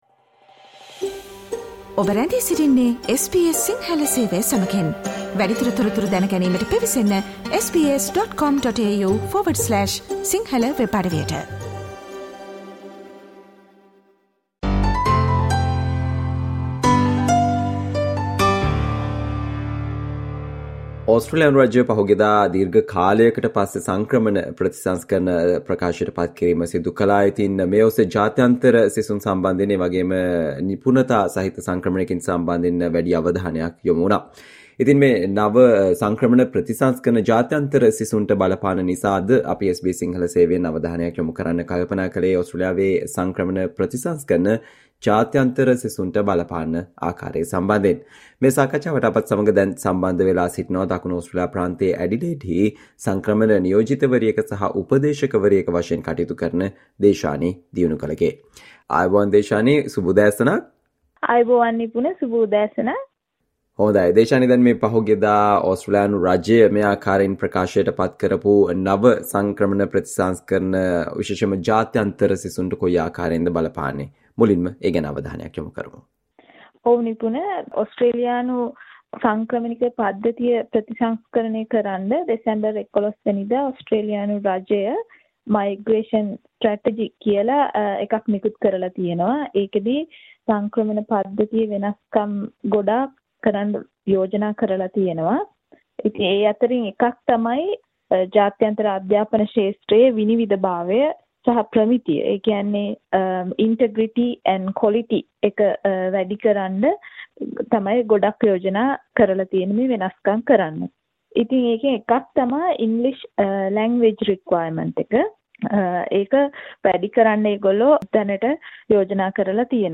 SBS Sinhala discussion on government's new reforms impact on Australia student visas